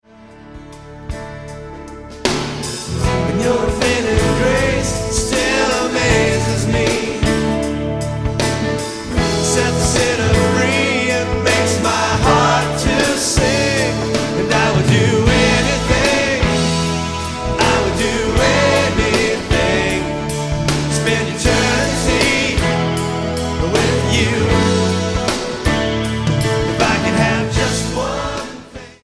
STYLE: MOR / Soft Pop
The musical arrangements are slick, but not too much so.